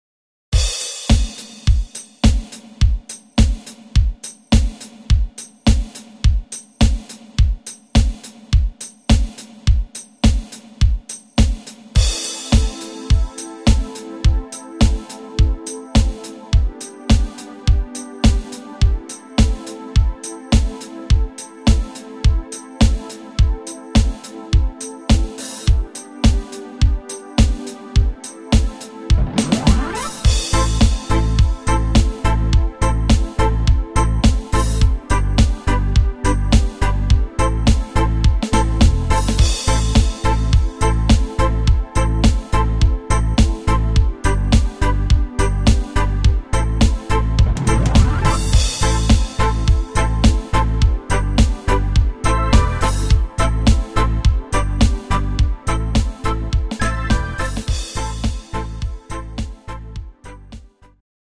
Rock Party Song